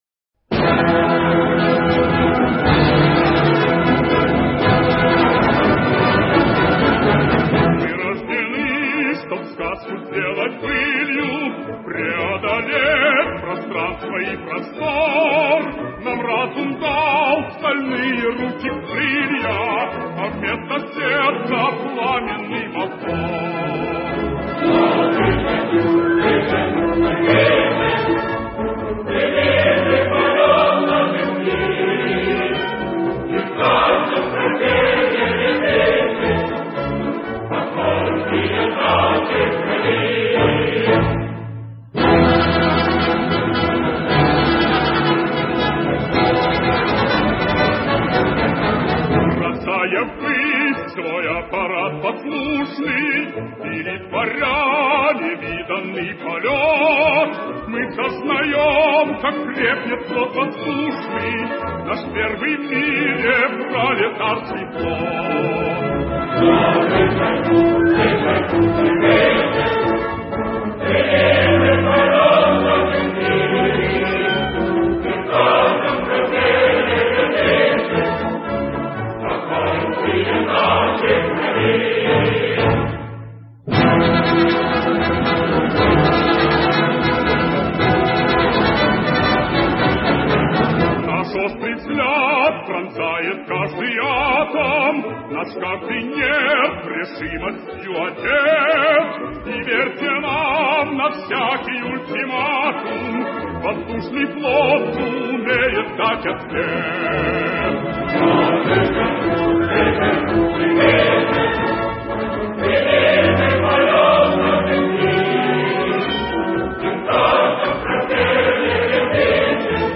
Музыка композитора Юлия Хайта, слова поэта Павла Германа — авиамарш «Всё выше».